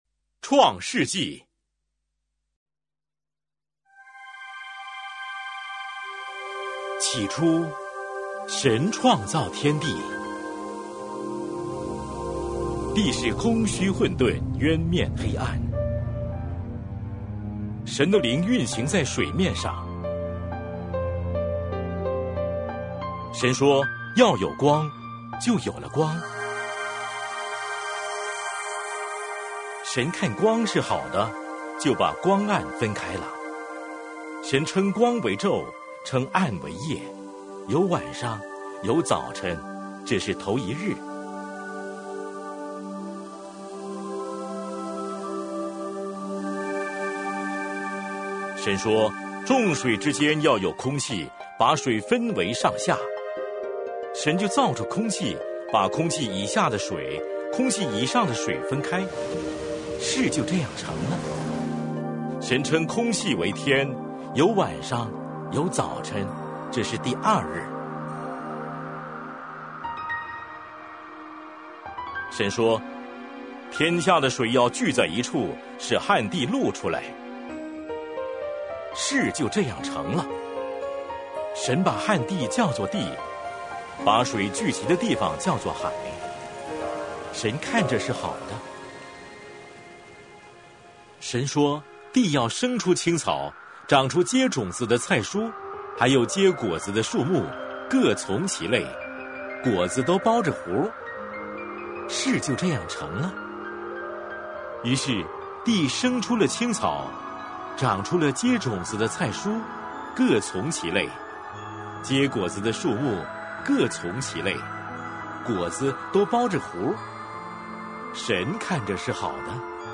每日读经 | 创世记1章